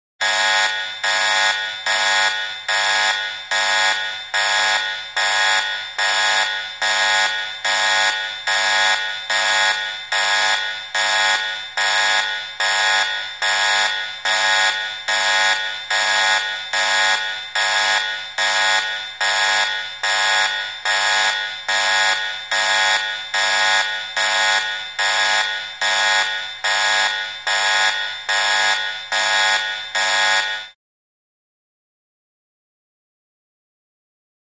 Alarm 4, Contemporary, Factory - Science Lab - Spaceship, Interior Warning Alarm, Midrange Rhythmic Buzzing with Slap Back Echo & Reverb.